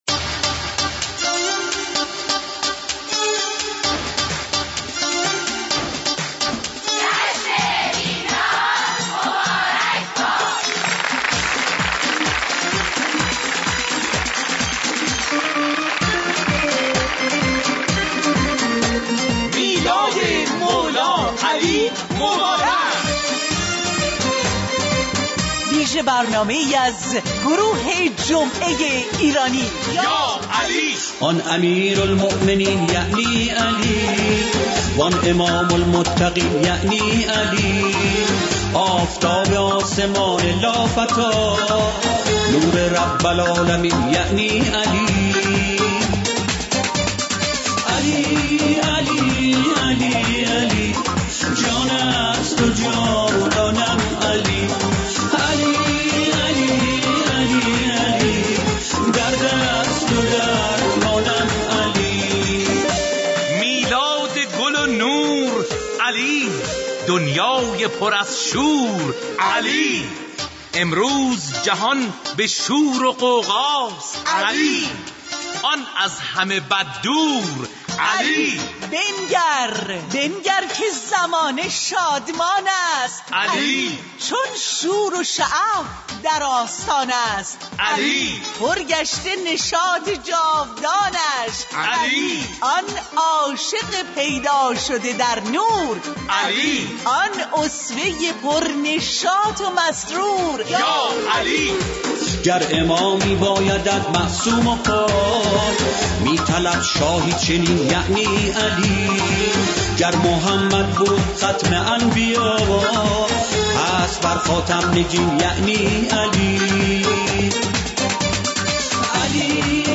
برنامه طنز جمعه ایرانی هر جمعه ساعت 9 تا 11:30 از رادیو ایران